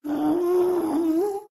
Sound / Minecraft / mob / ghast / moan5.ogg
moan5.ogg